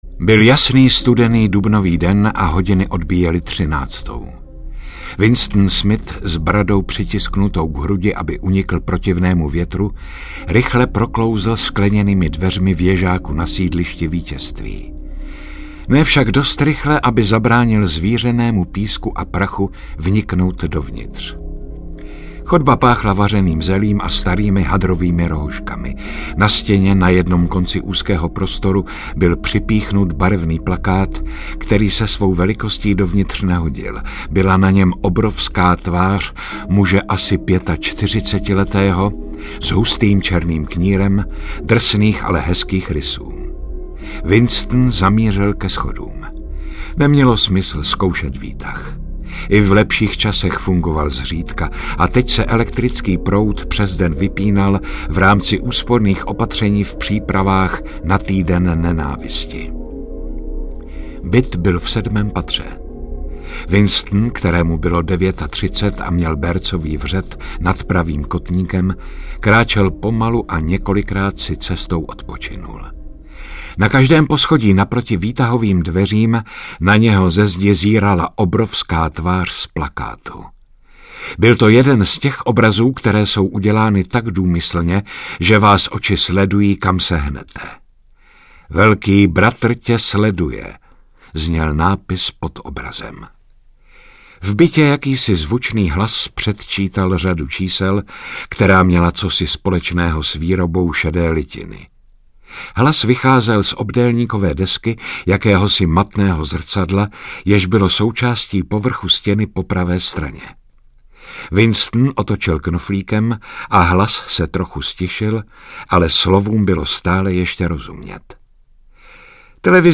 1984-audiostory.mp3